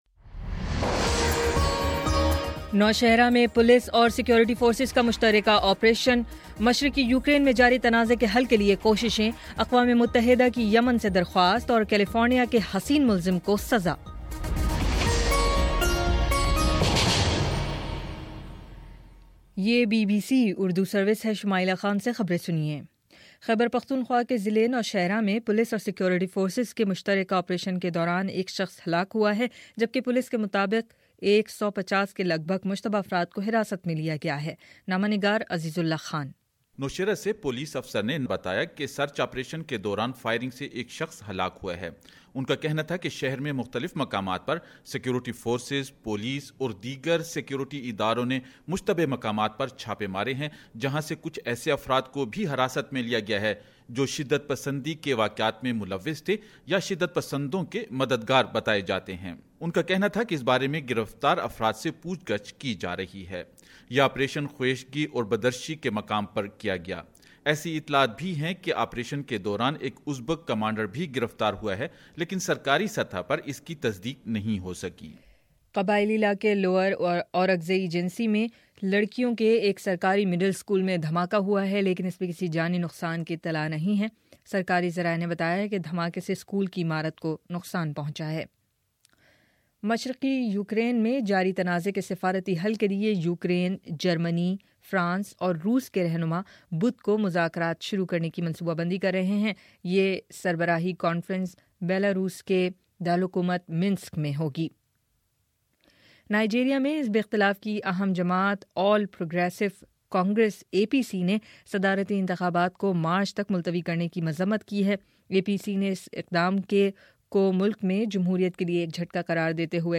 فروری08 : شام سات بجے کا نیوز بُلیٹن